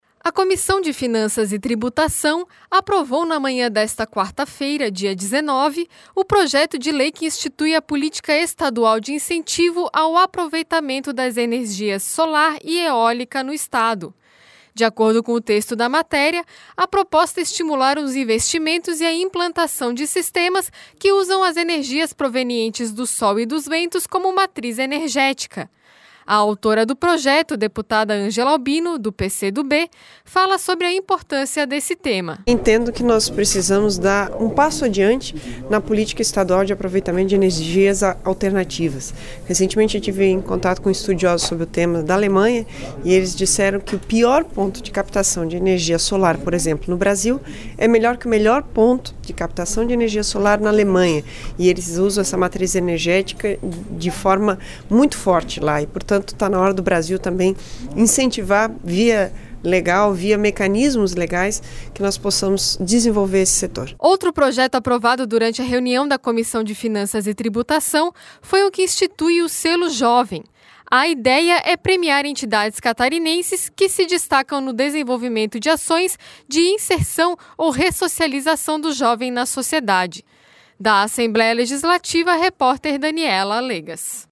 Entrevista: Deputada Ângela Albino (PCdoB).